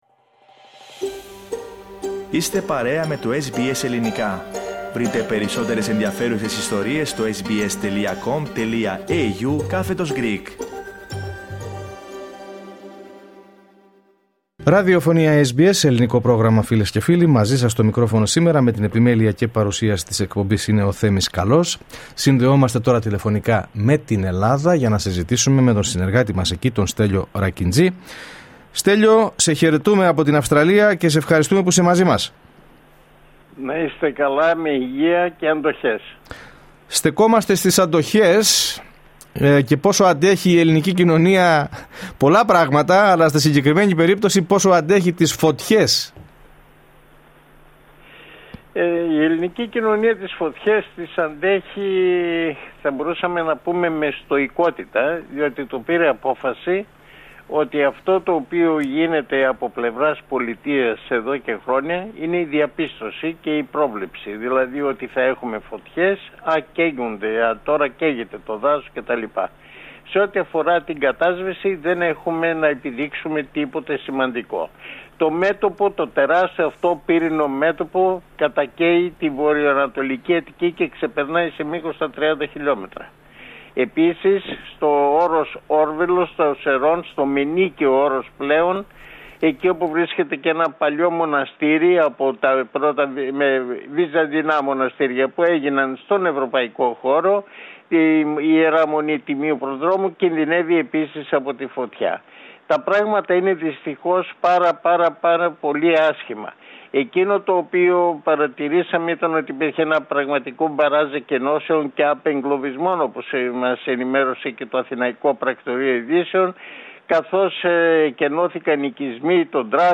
Ακούστε την εβδομαδιαία ανταπόκριση από την Αθήνα